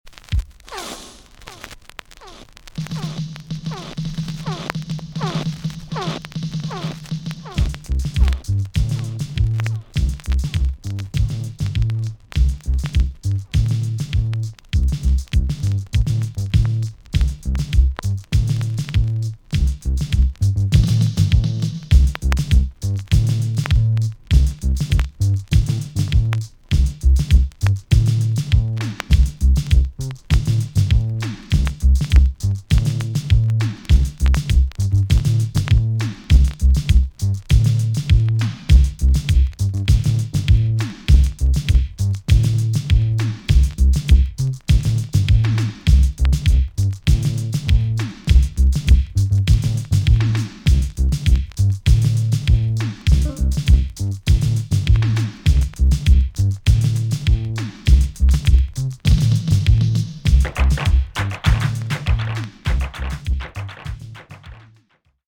TOP >80'S 90'S DANCEHALL
B.SIDE Version
VG+ 少し軽いチリノイズが入ります。